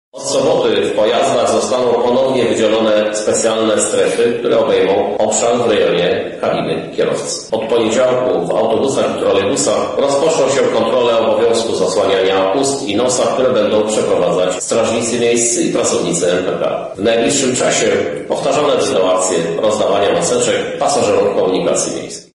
Wprowadzenie strefy żółtej oznacza przede wszystkim obowiązek zasłaniania ust i nosa, bardzo proszę mieszkańców Lublina, by nie lekceważyli tych wymogów – mówi Krzysztof Żuk Prezydent Miasta: